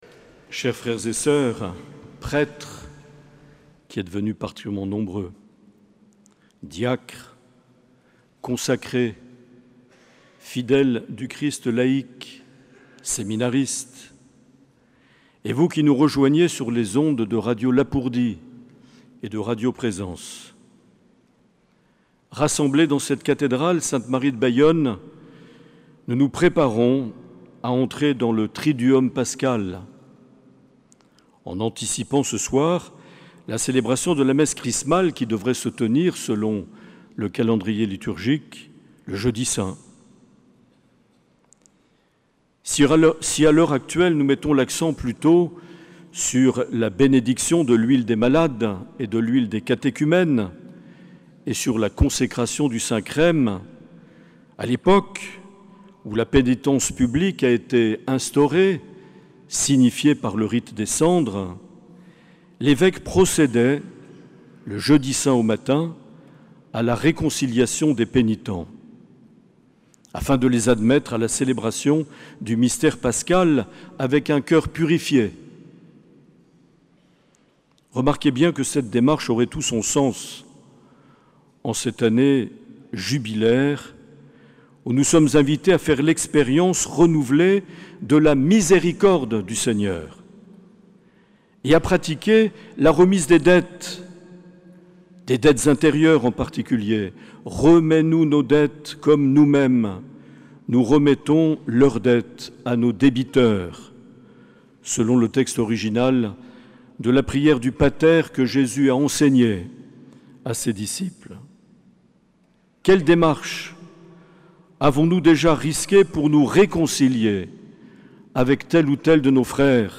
Messe Chrismale à la cathédrale de Bayonne
Homélie de Mgr Marc Aillet le 15 avril 2025.